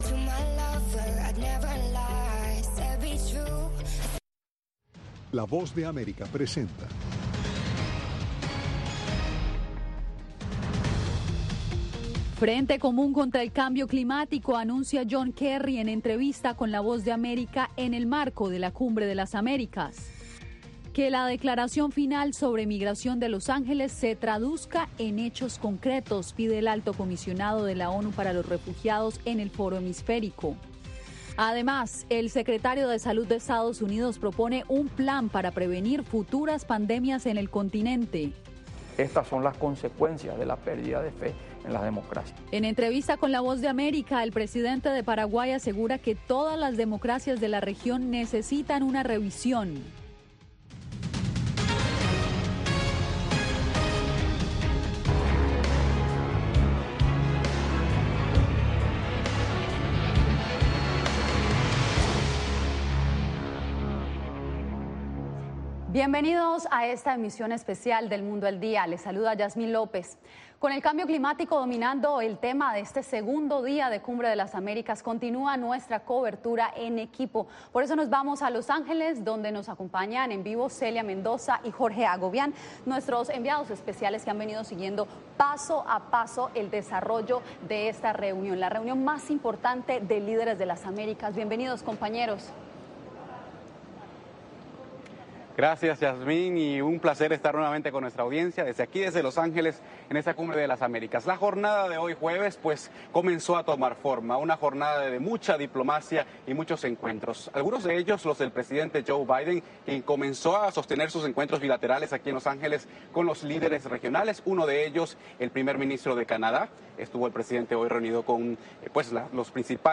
Frente común contra el cambio climático anuncia John Kerry en el marco de la Cumbre de las Américas. Entrevista con la Voz de América. Que la declaración final de Los Ángeles sobre migración se traduzca en hechos concretos, pide el Alto Comisionado de la ONU para los Refugiados.